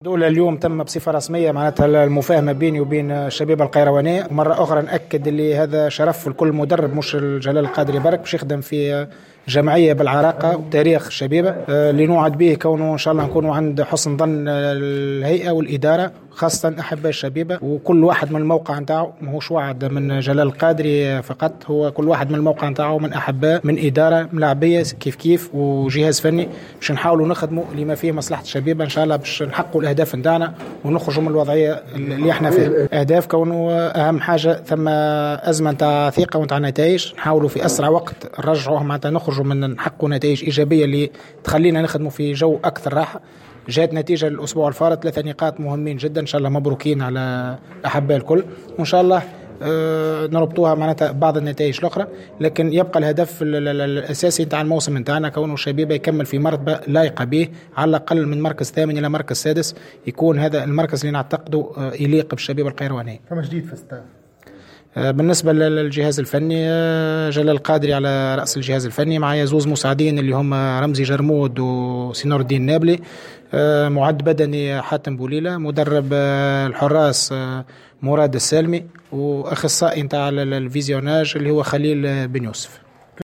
عقدت اليوم الهيئة المديرة للشبيبة الرياضية القيروانية ندوة صحفية لتقديم المدرب الجديد للفريق جلال القادري الذي تولى المهمة خلفا للفرنسي باسكال جانان.